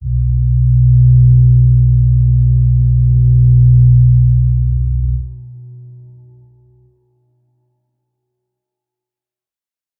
G_Crystal-B2-pp.wav